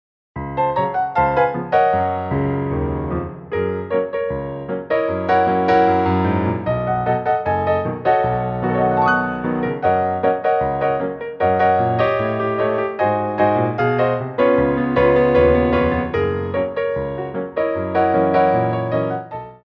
Medium Allegro
4/4 (16x8)